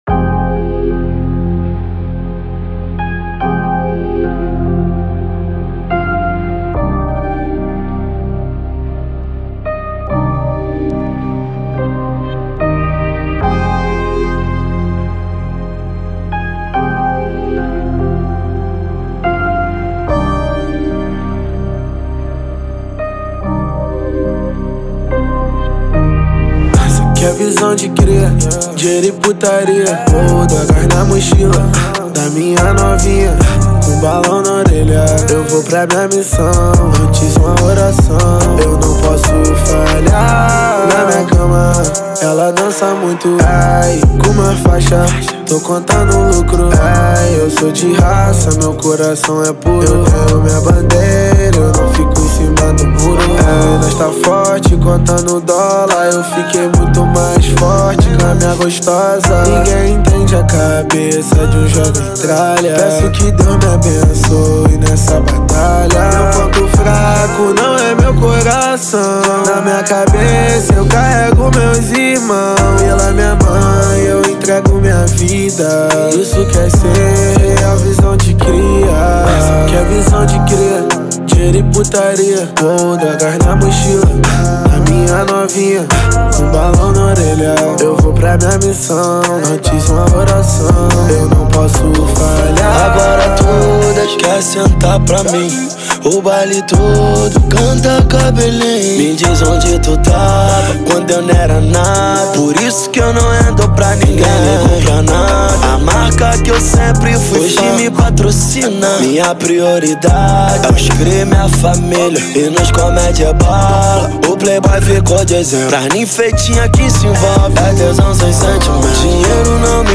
2024-07-25 21:48:35 Gênero: Funk Views